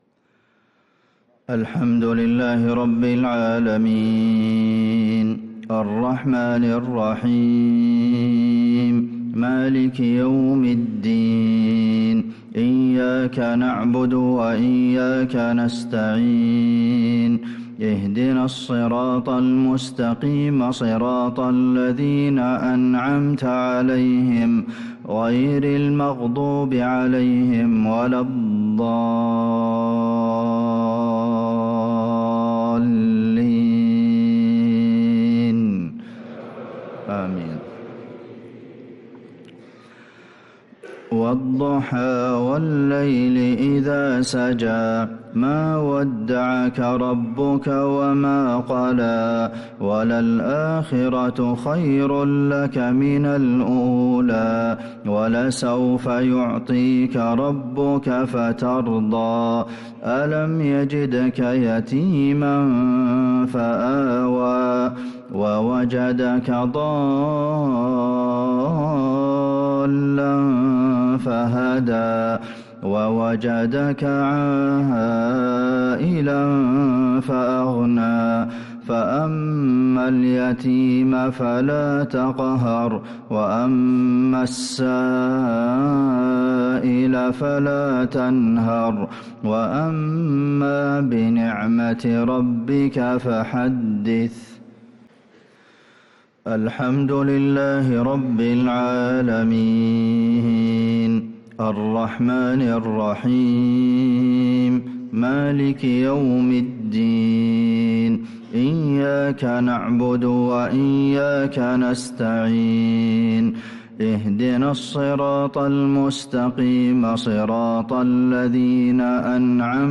صلاة المغرب ٤-٨-١٤٤٦هـ | سورة الضحى و العاديات | Maghrib prayer from Surah ad-Duha & al-`Adiyat | 3-2-2025 > 1446 🕌 > الفروض - تلاوات الحرمين